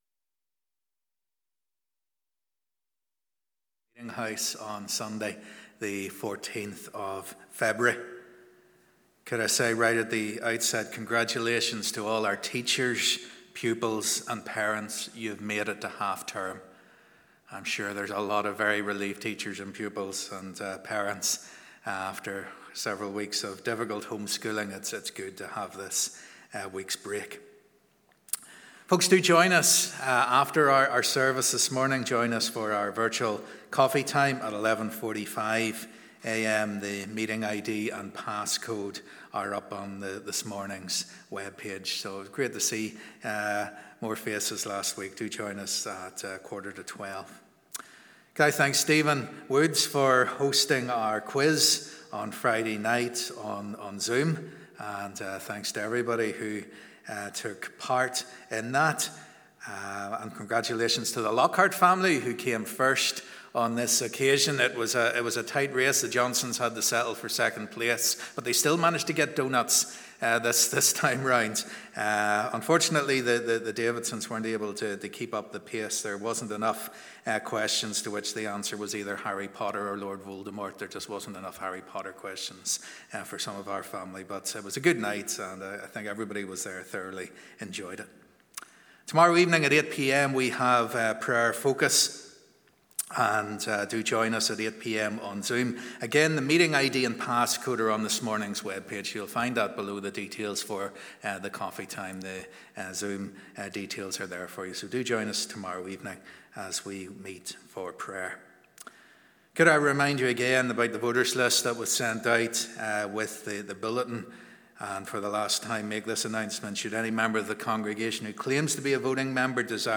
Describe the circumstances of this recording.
In our Family Service today we are going to explore the history and significance of Saint Valentine’s Day and see whether it has any relevance for disciples of Jesus Christ today.